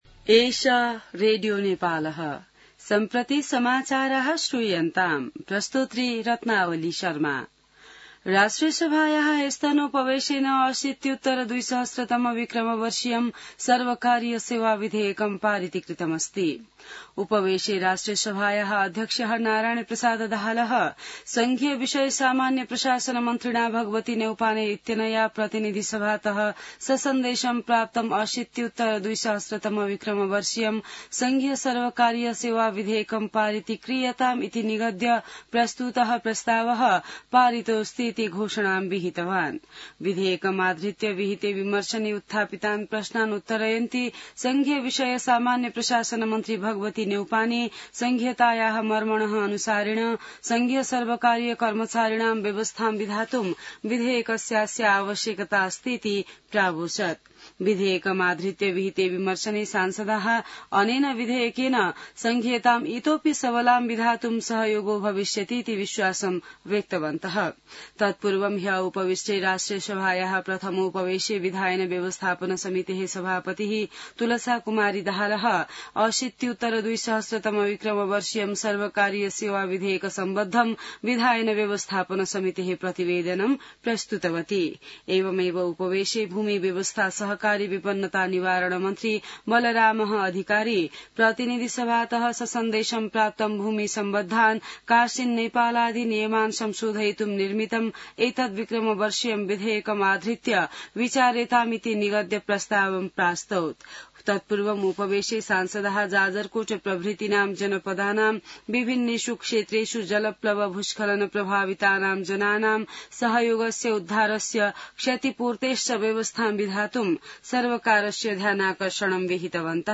An online outlet of Nepal's national radio broadcaster
संस्कृत समाचार : १८ भदौ , २०८२